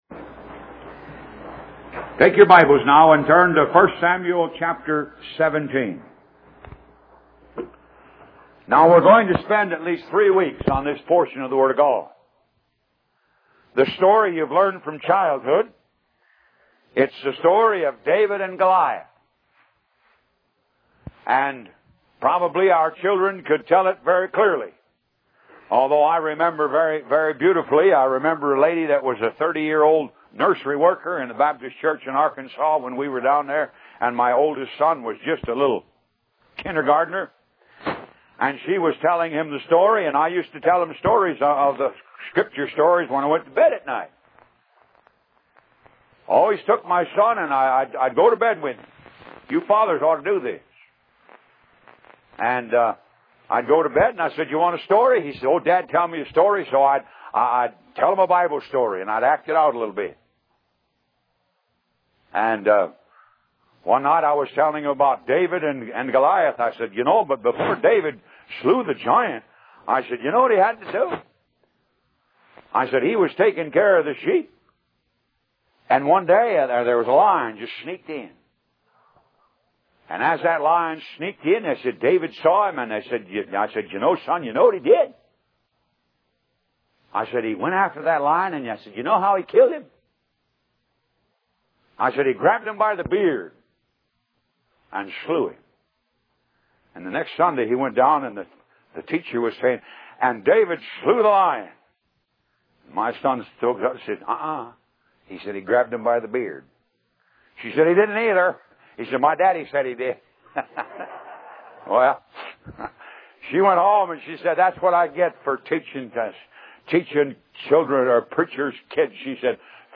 Talk Show Episode, Audio Podcast, Moga - Mercies Of God Association and David And Goliath on , show guests , about David And Goliath, categorized as Health & Lifestyle,History,Love & Relationships,Philosophy,Psychology,Christianity,Inspirational,Motivational,Society and Culture